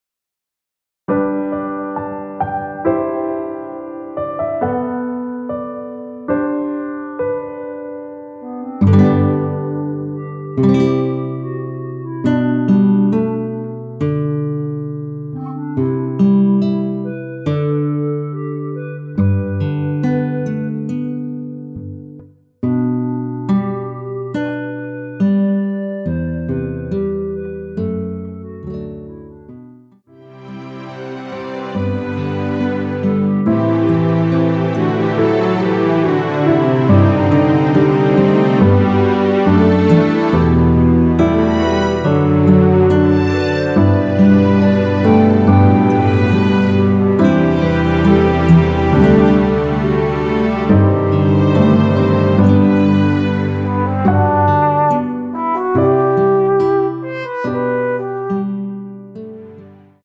원키에서(-2)내린 멜로디 포함된 MR입니다.
앞부분30초, 뒷부분30초씩 편집해서 올려 드리고 있습니다.